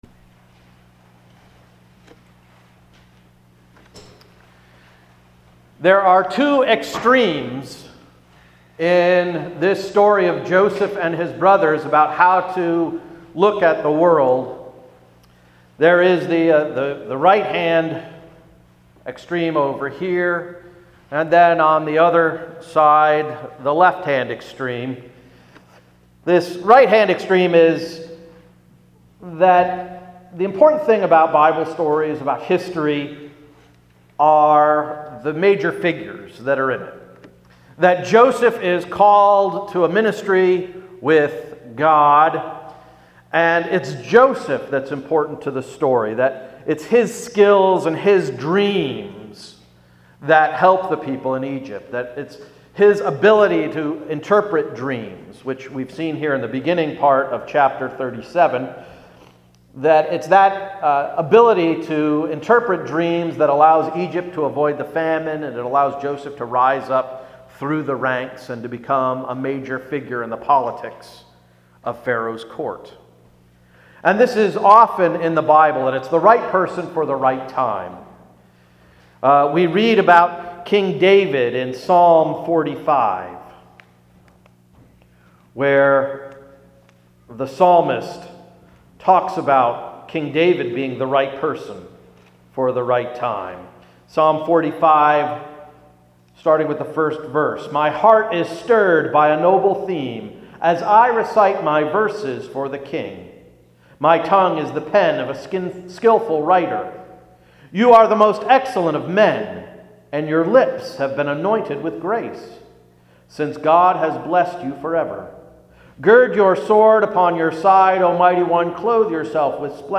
February 28, 2016 Sermon–“Living the Dream”